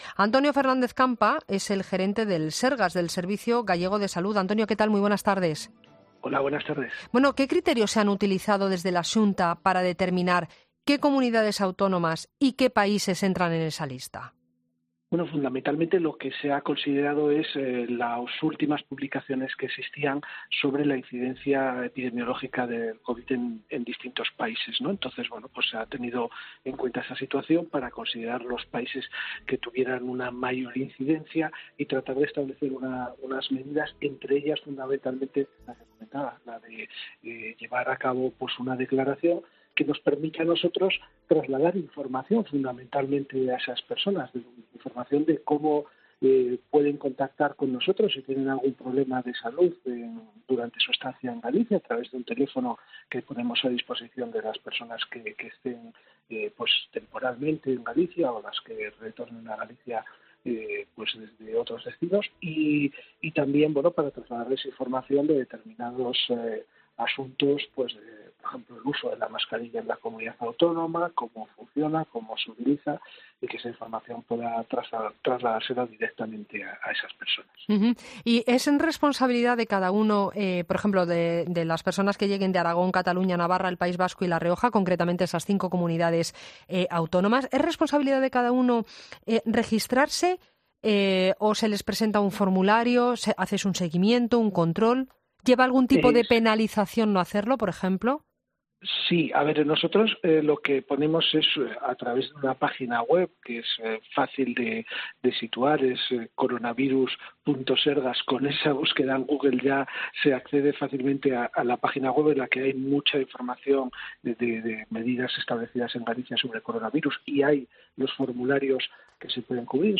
El gerente del Servicio Gallego de Salud (SERGAS), Antonio Fernández – Campa, ha sido entrevistado en La Linterna para analizar las mediadas que ha implementado la Comunidad Autónoma gallega contra el coronavirus, y la situación en la que se encuentran los jugadores del Fuenlabrada contagiados.